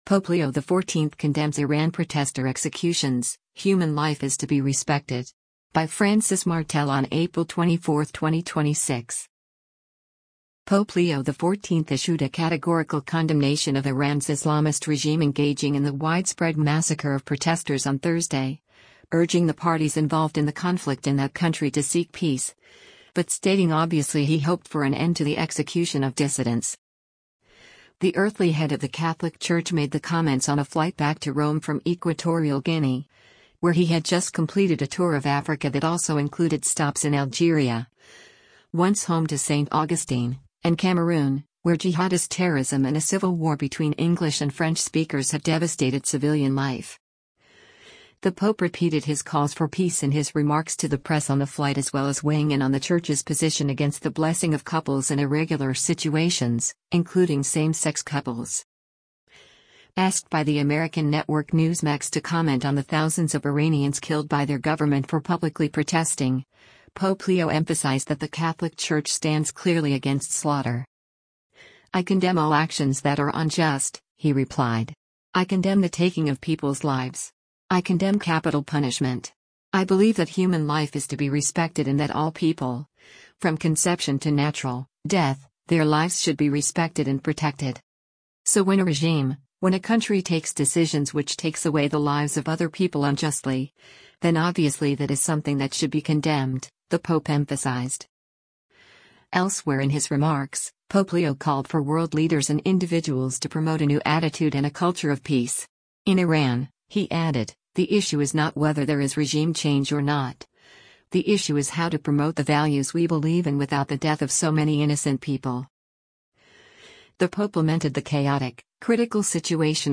Pope Leo XIV speaks to journalists aboard the papal flight from Malabo to Rome, Thursday,